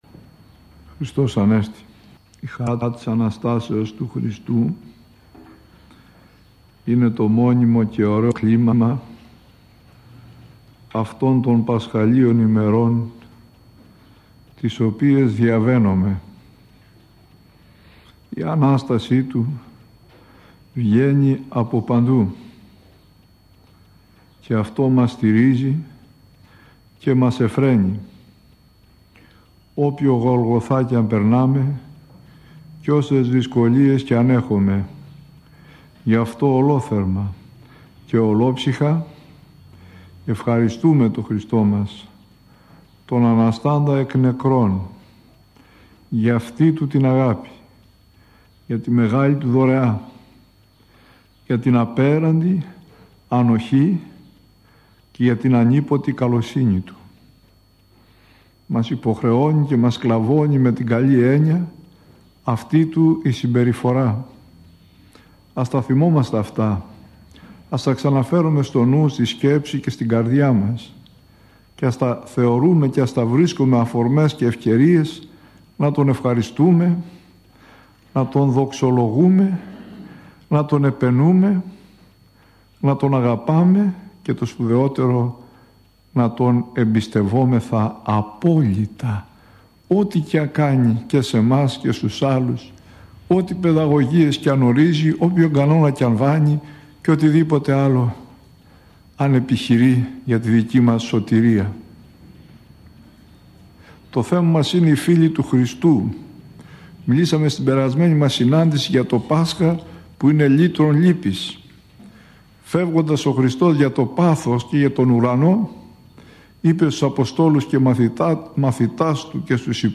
Η Ανάσταση του Χριστού μας – ηχογραφημένη ομιλία
Η ομιλία αυτή έγινε στο ίδρυμα “Άγιος Νεκτάριος”, οδός Ισαύρων 39, στα Εξάρχεια των Αθηνών.